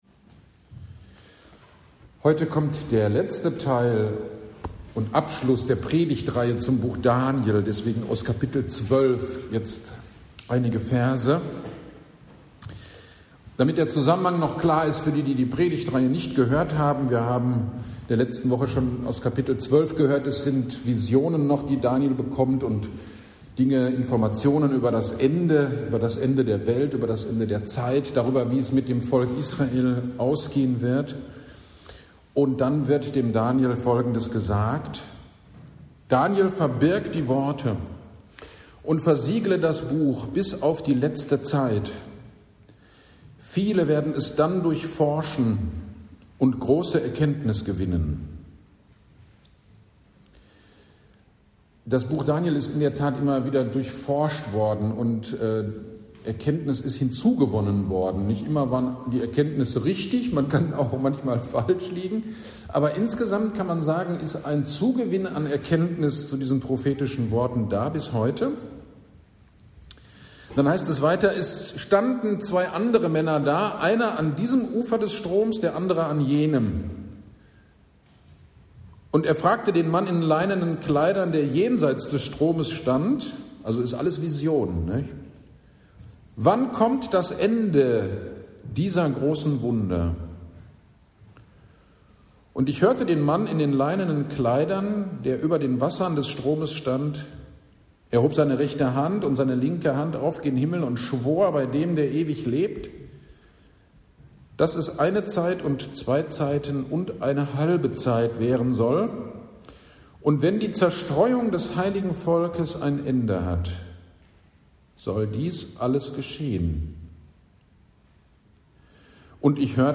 AGD am 24.11.24 – Ewigkeitssonntag – Predigt zu Daniel 12,3-13